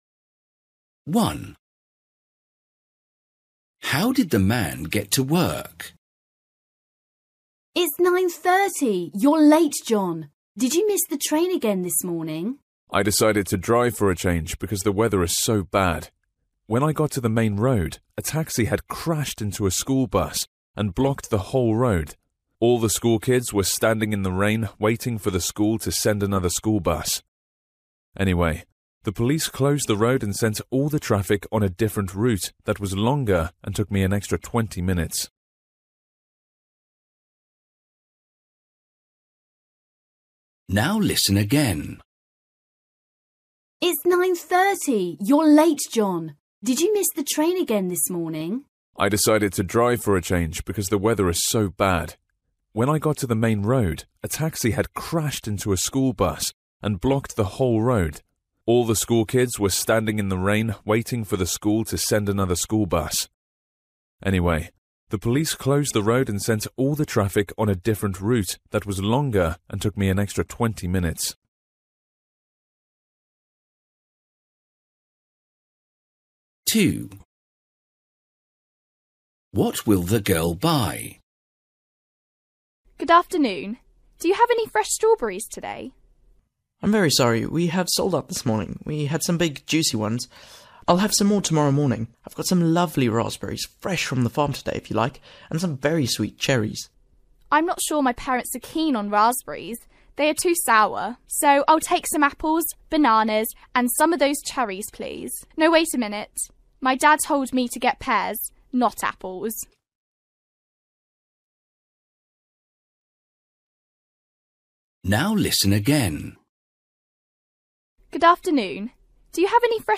Listening: Everyday Conversations and Activities